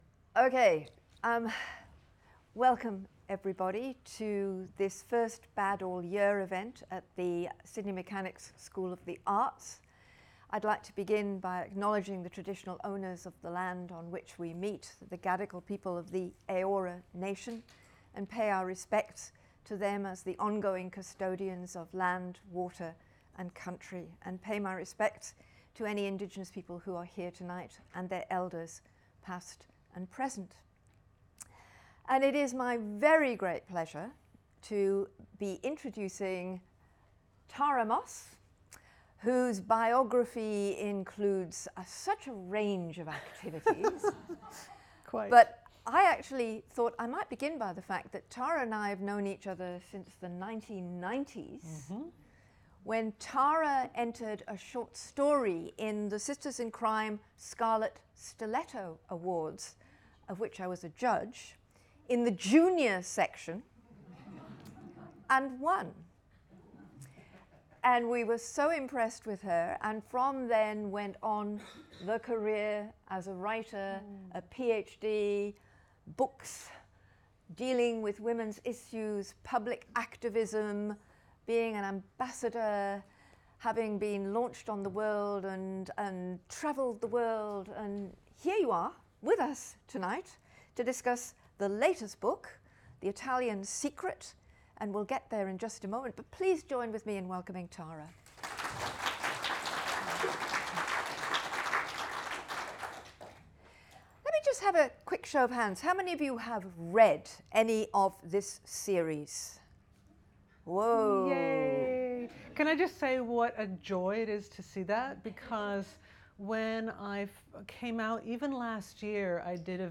If you’ve ever wondered what happens when you put a bestselling author, a sharp interviewer and a room full of curious readers in the same space, wonder now more!